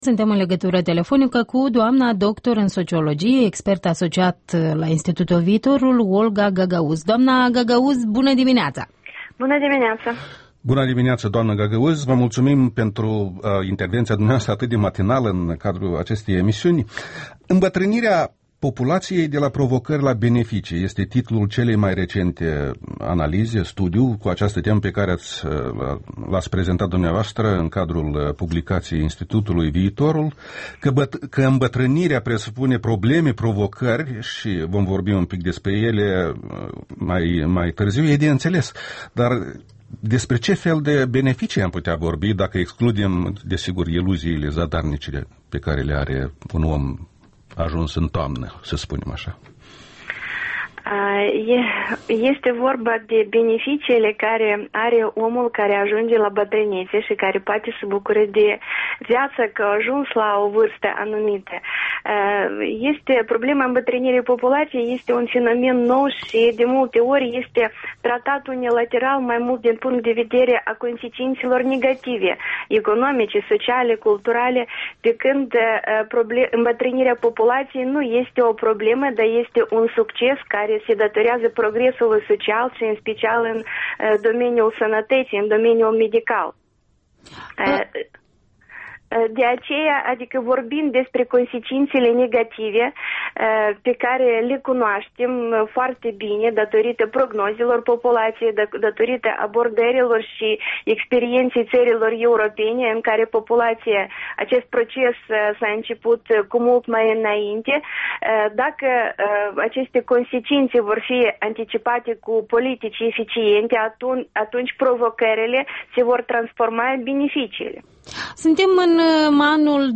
Interviul dimineții la REL